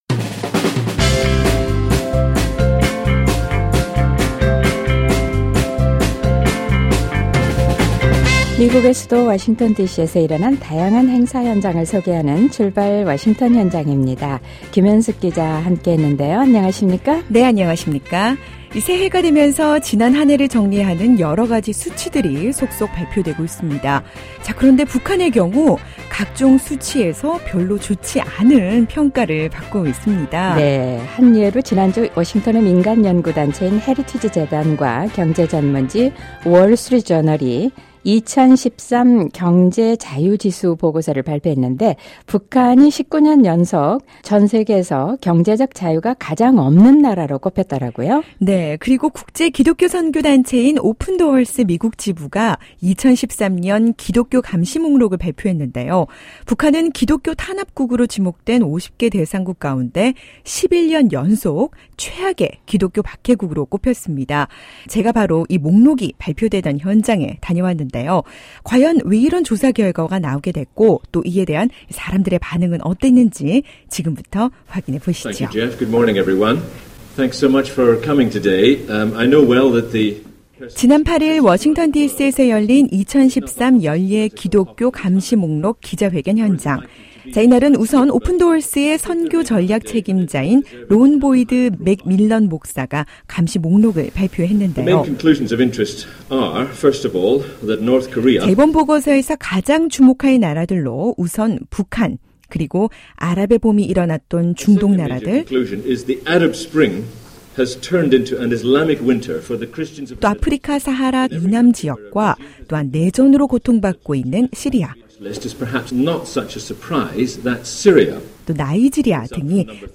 지난 8일 워싱턴디씨에서 열렸던 기독교감시목록 발표 현장을 소개합니다.